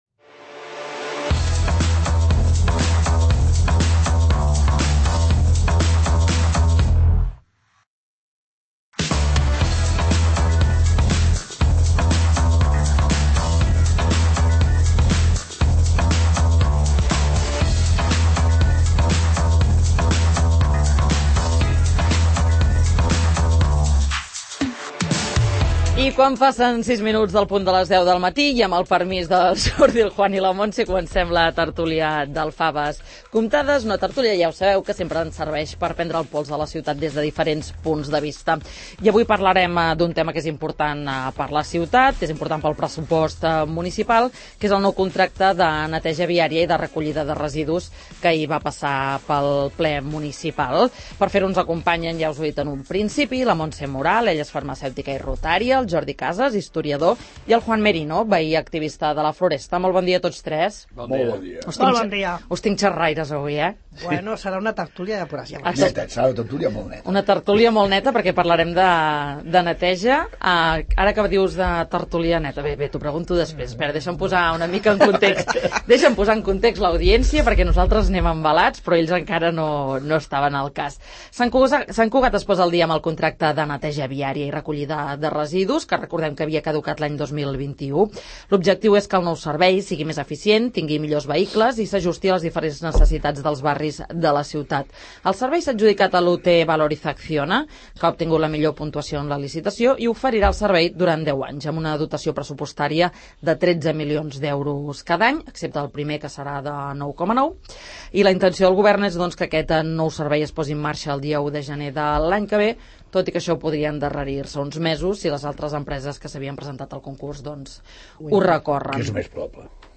El nou servei de neteja viària i recollida de residus, a la tertúlia del ‘Faves comptades’